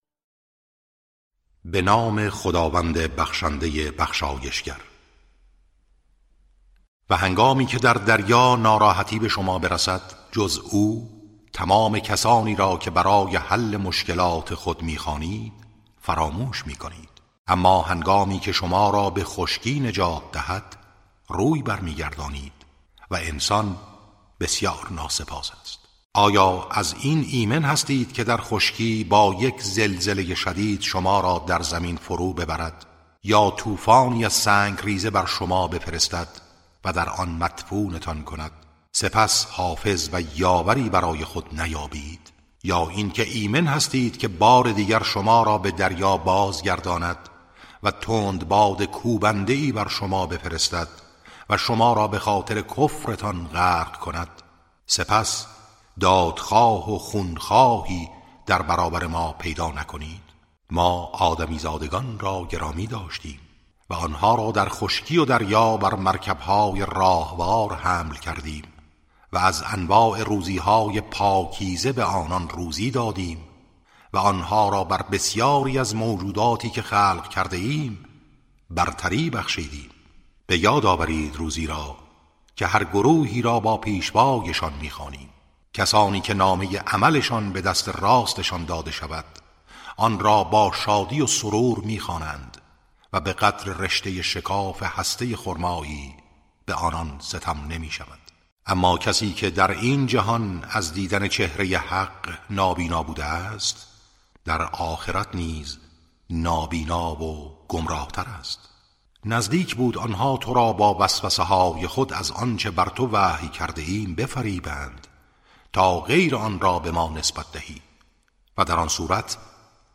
ترتیل صفحه ۲۸۹ از سوره اسراء(جزء پانزدهم)